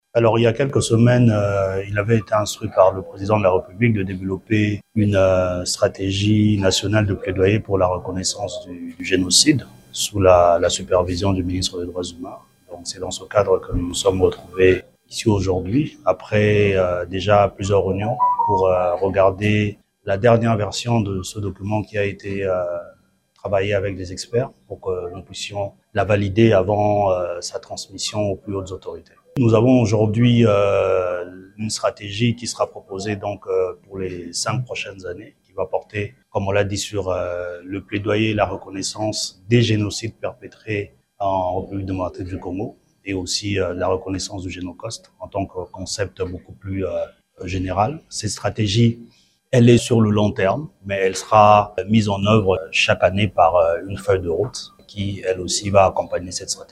A l’issue de cette rencontre, le Directeur général du FONAREV, Patrick Fata, a indiqué qu’après plusieurs semaines de travail, le ministère des Droits humains a répondu à l’instruction du Chef de l’État en dotant le pays d’une stratégie nationale en matière de reconnaissance des génocides commis en RDC.